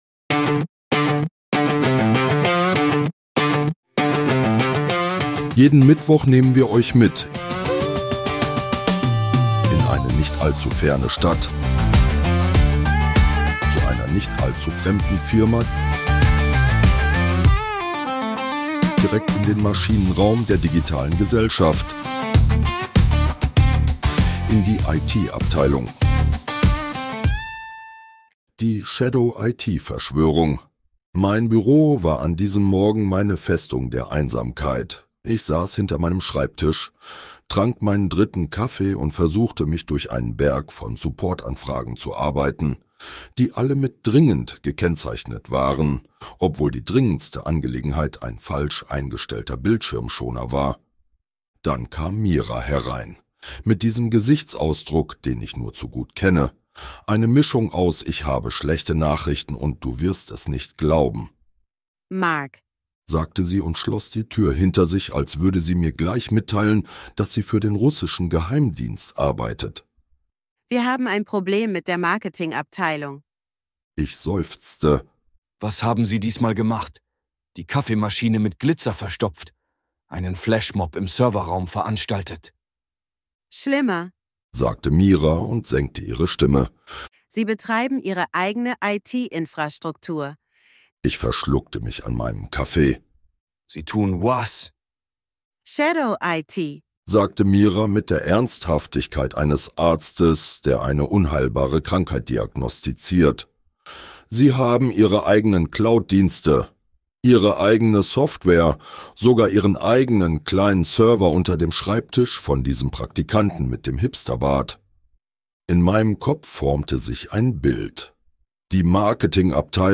Dieser Podcast ist Comedy.